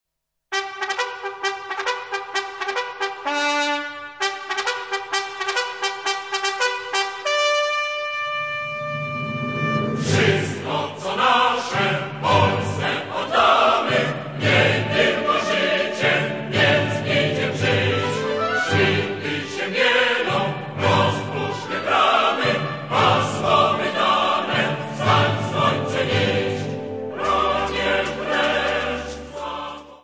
24 Polish Scout songs.